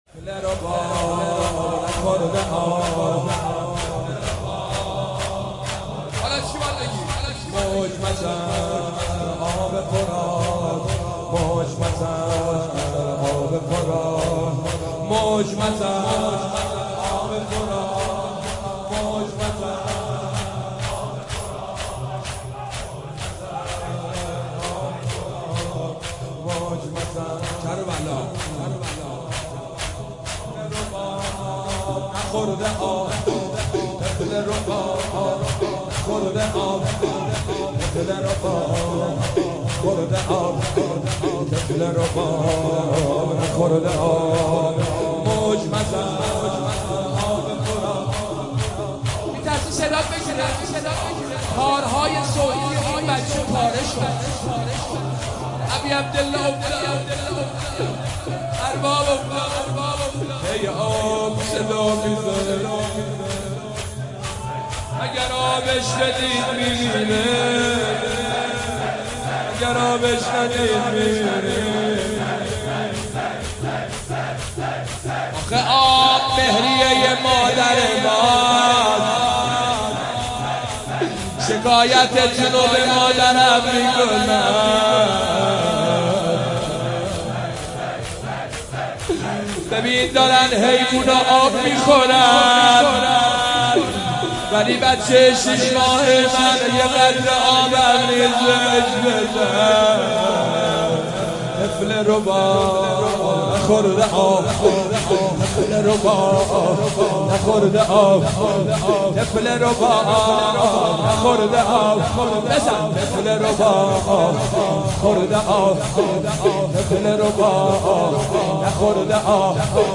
شب هفتم محرم
نوحه
مداحی صوتی
شور